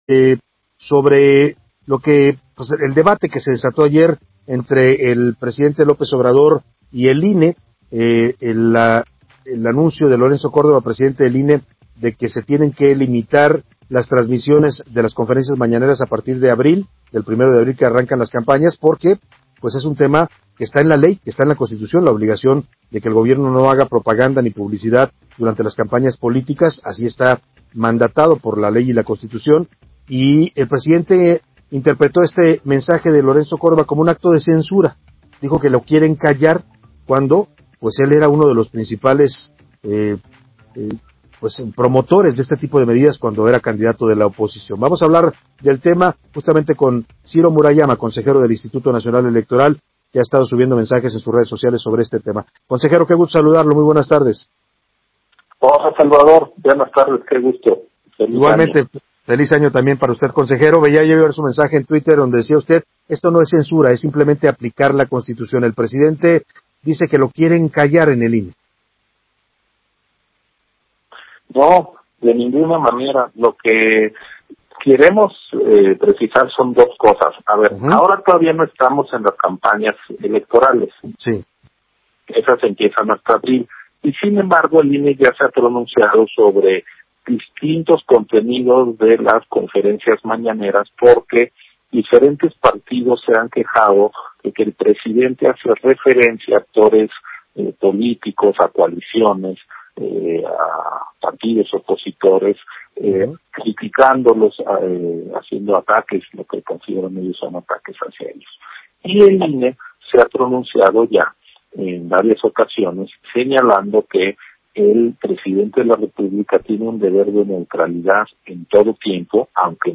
El Presidente de la República tiene un deber de neutralidad en proceso electoral: Murayama en entrevista con Salvador García Soto